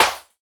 Snare (39).wav